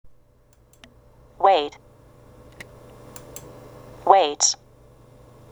３】sをつけて(語尾のtと合わせて)「tｓ(ツ)」と発音する動詞